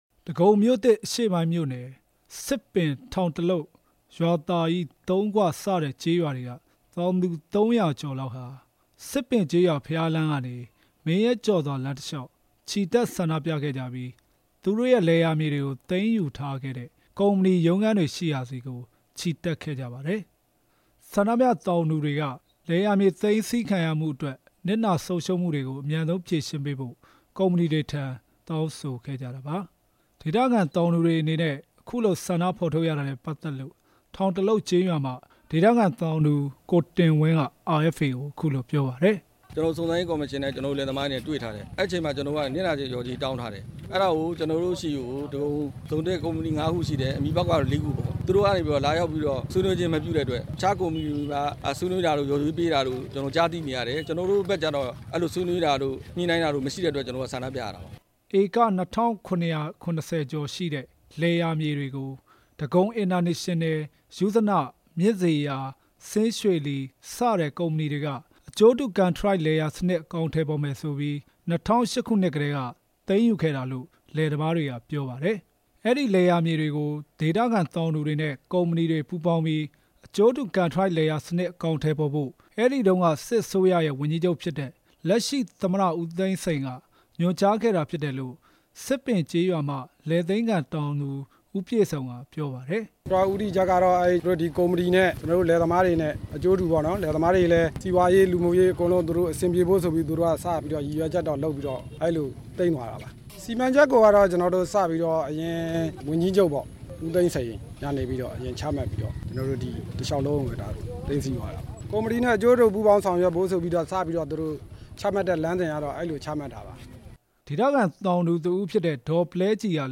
ဆန္ဒပြပွဲအကြောင်း တင်ပြချက်